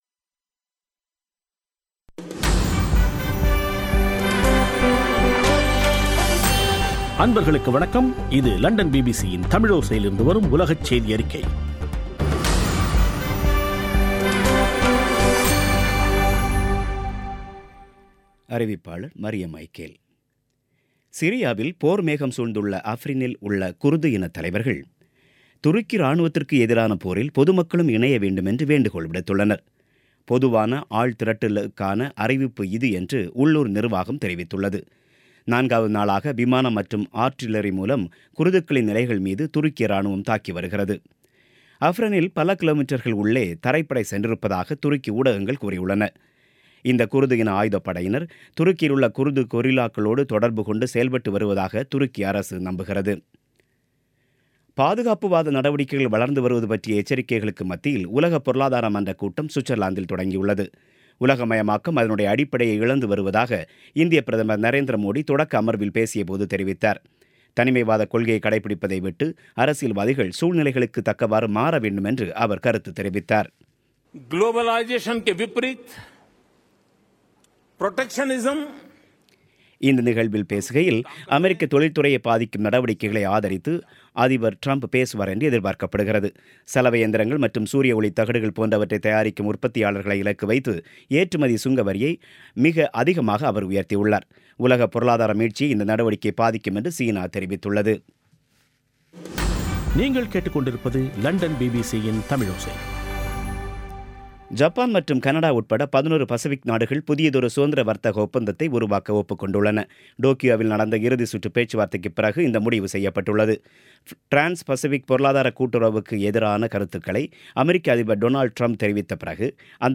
பிபிசி தமிழோசை செய்தியறிக்கை (23/01/2018)